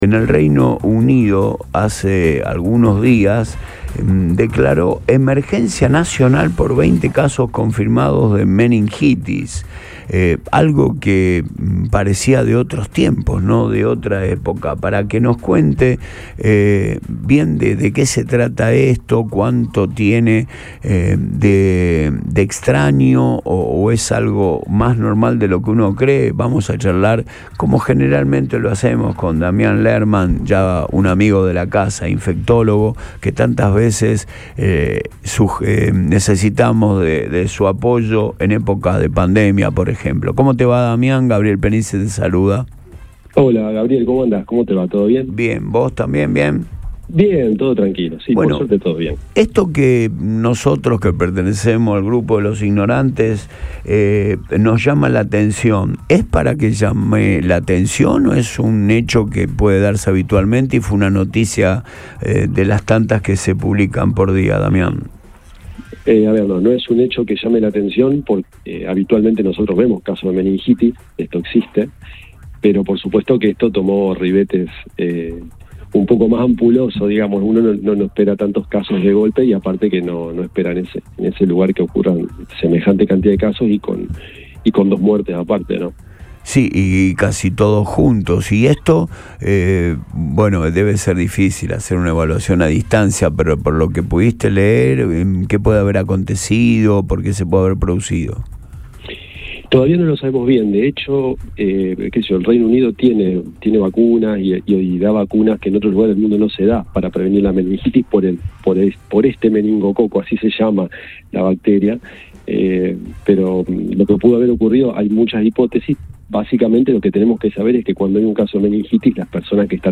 El profesional habló con Boing 97.3. Y detalló que la meningitis puede ser causada por virus o bacterias, siendo estas últimas las más peligrosas, causando una mortalidad mayor.